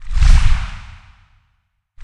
PixelPerfectionCE/assets/minecraft/sounds/mob/enderdragon/wings5.ogg at mc116
wings5.ogg